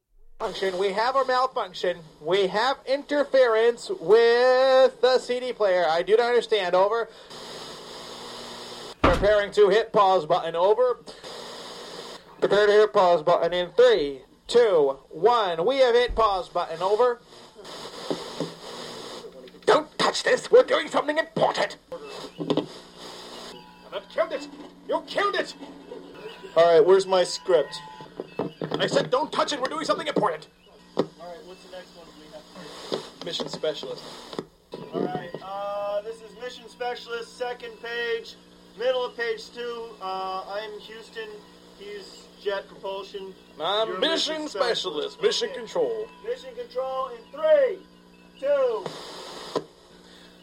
描述：这是我即将录制的一个广播节目的片段我们用对讲机来模拟美国国家航空航天局的无线电通讯。
Tag: 收音机 出来走 声音 画外音